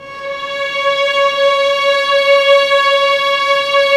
Index of /90_sSampleCDs/Roland L-CD702/VOL-1/STR_Vlns 6 mf-f/STR_Vls6 mf amb